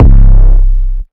808_Kitchen.wav